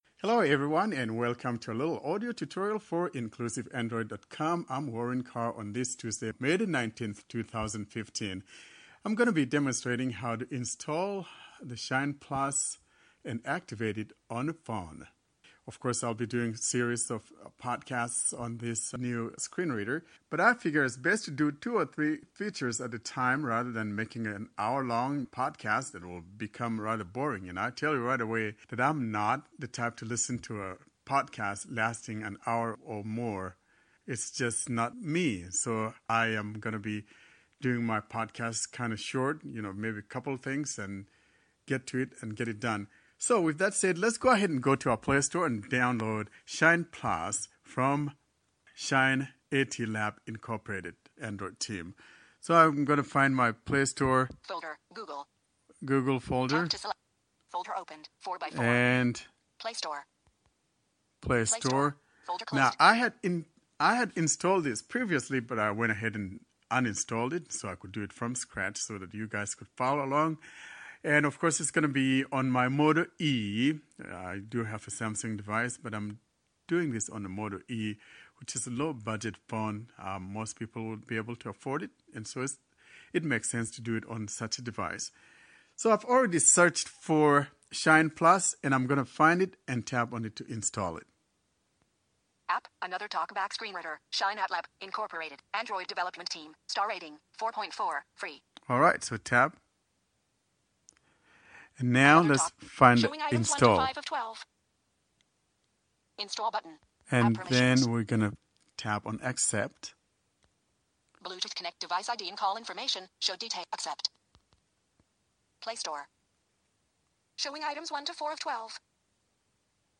This is an audio tutorial regarding the installation of the ShinePlus (Another Talkback ScreenReader) for beginners.